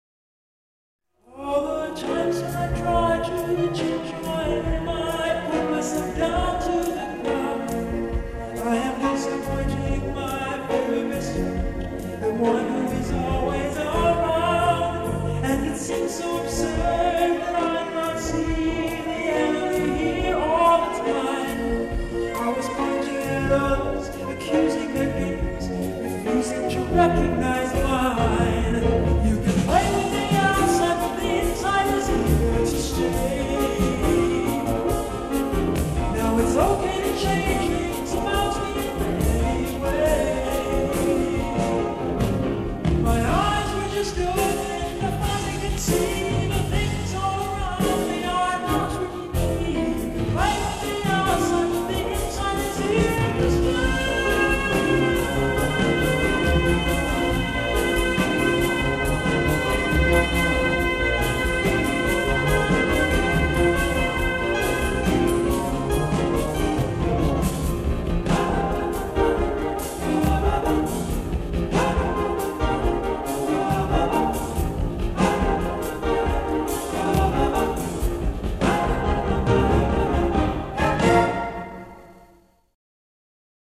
Jazz 1982 1:23